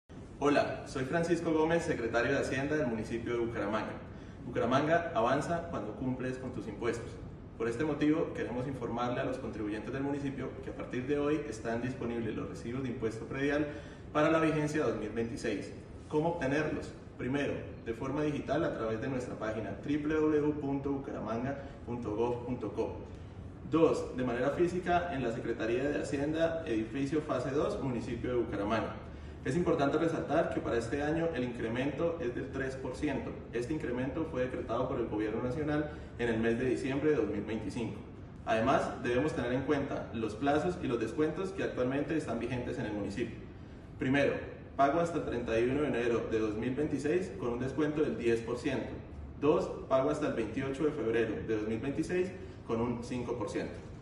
Francisco Gomez, Secretario de Hacienda de Bucaramanga